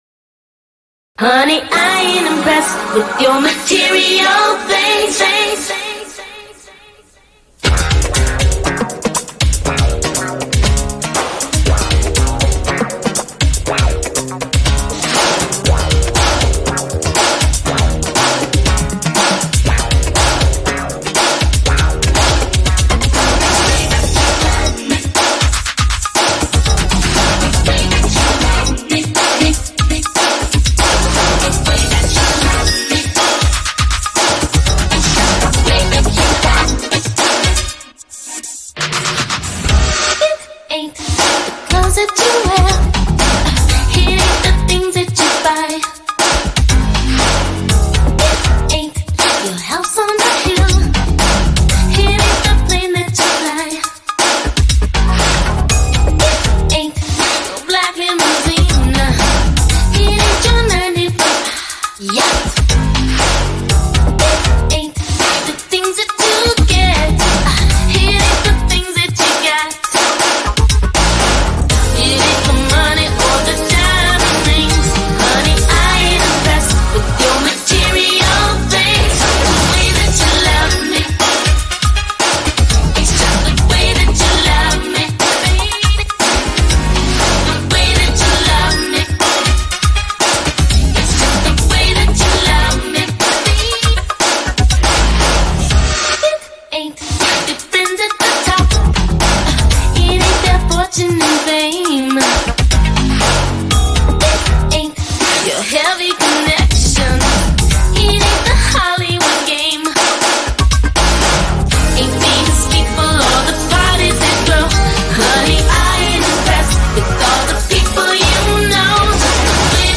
Dance-Pop, Contemporary R&B